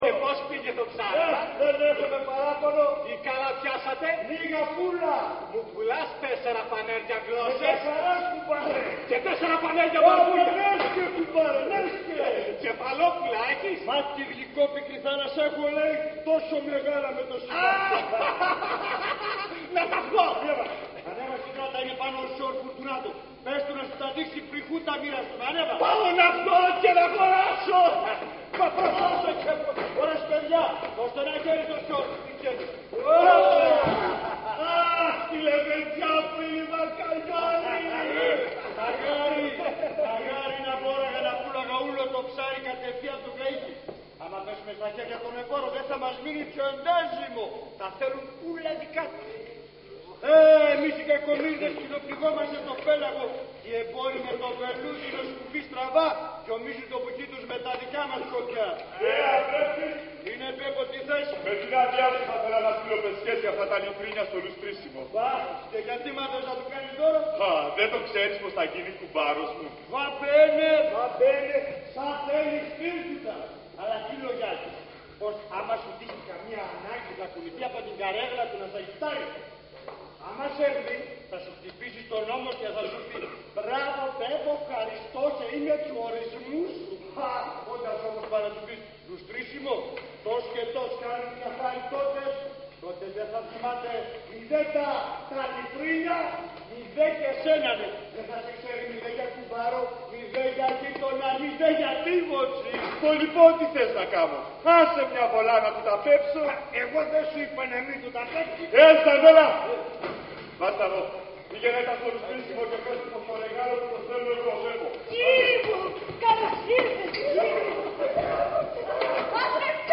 Ηχογράφηση Παράστασης